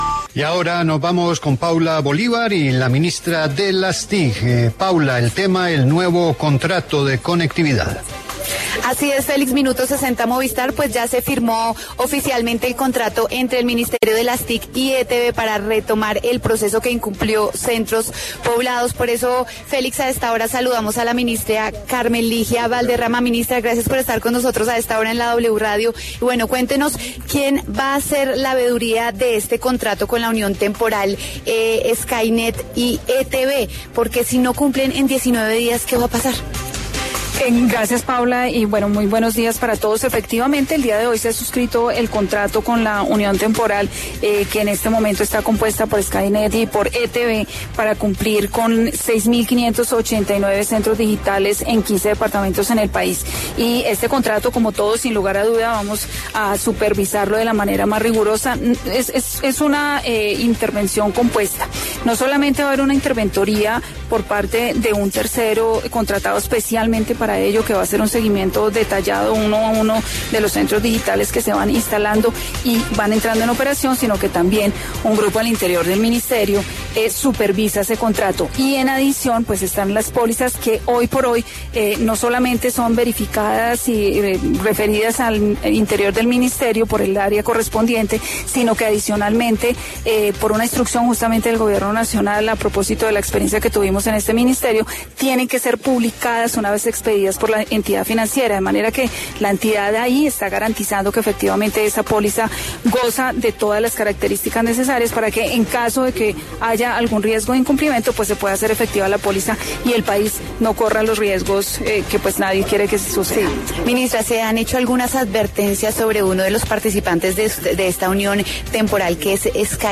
La ministra Carmen Ligia Valderrama se pronunció en La W sobre la Unión Temporal entre ETB y Skynet, que se firmará este jueves 19 de mayo y que instalará 6.589 centros digitales.